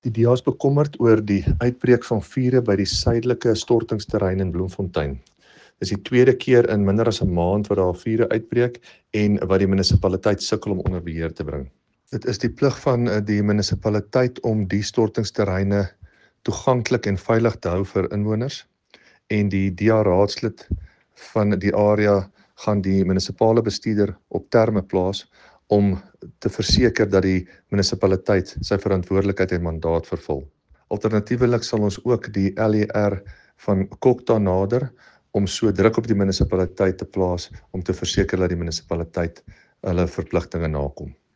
Afrikaans soundbites by David van Vuuren MPL and